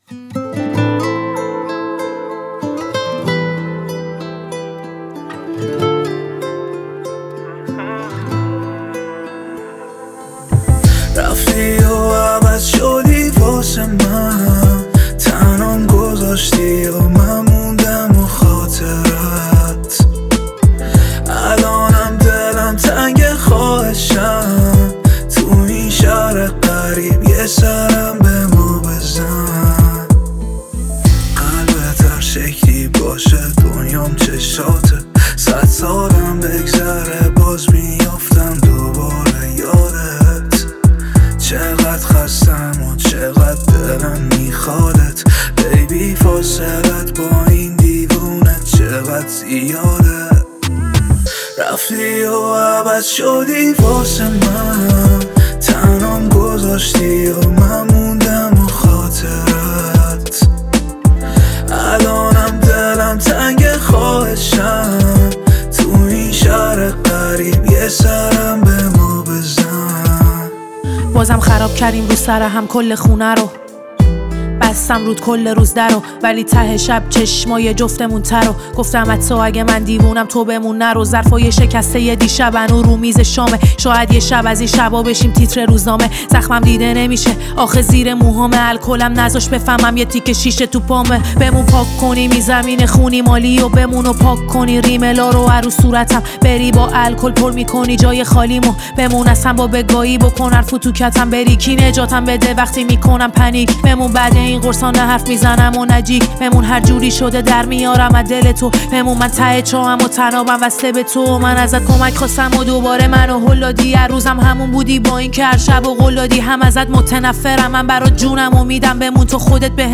رپ
آهنگ با صدای زن